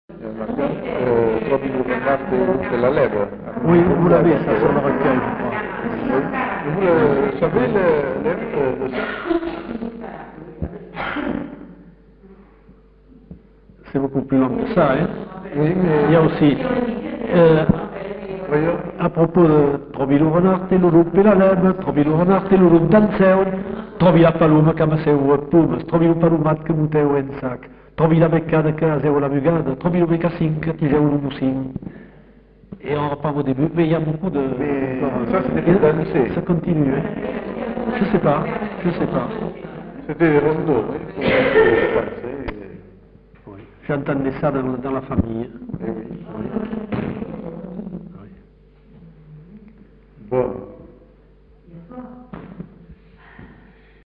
Aire culturelle : Bazadais
Genre : chant
Effectif : 1
Type de voix : voix d'homme
Production du son : chanté
Classification : enfantines diverses